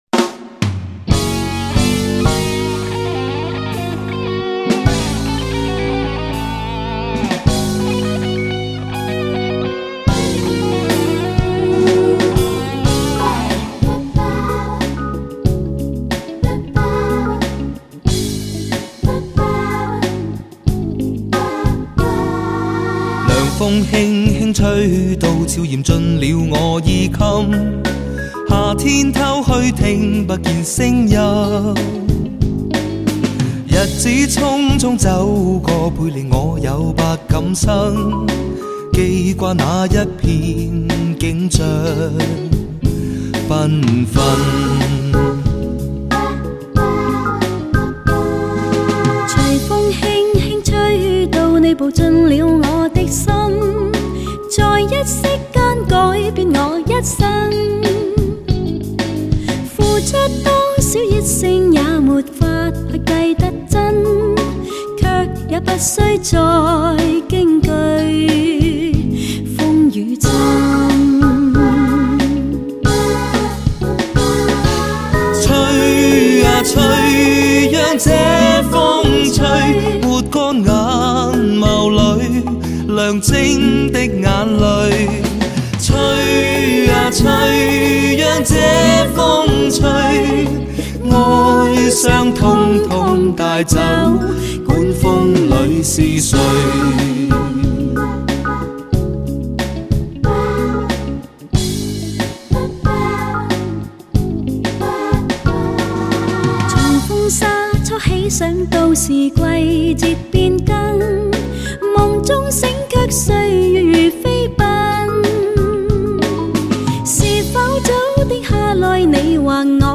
磁性魅力嗓音，人声格外暖厚动人，